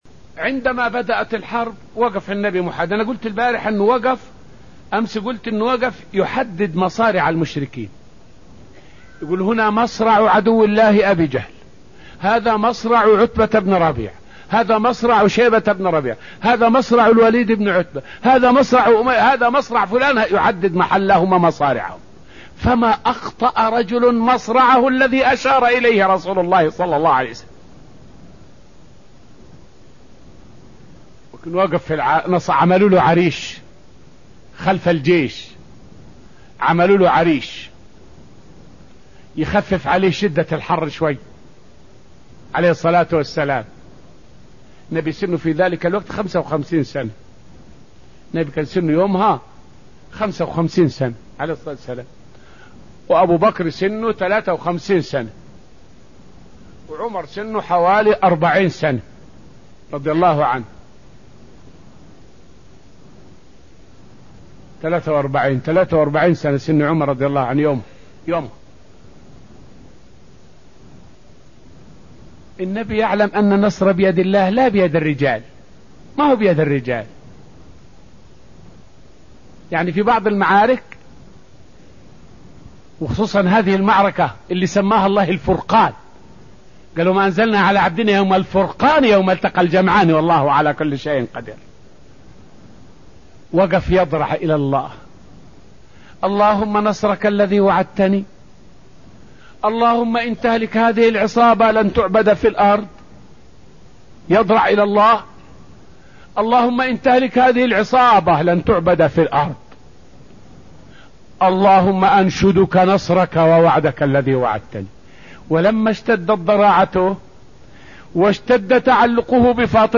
فائدة من الدرس الأول من دروس تفسير سورة آل عمران والتي ألقيت في المسجد النبوي الشريف حول أخذ النبي صلى الله عليه وسلم برأي أحد المسلمين في غزوة بدر.